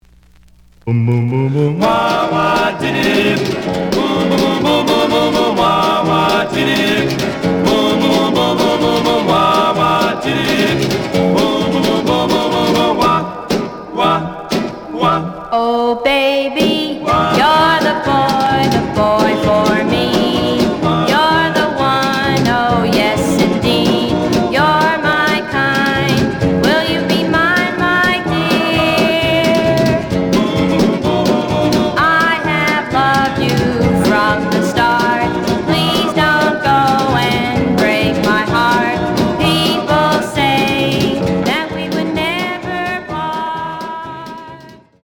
●Genre: Rhythm And Blues / Rock 'n' Roll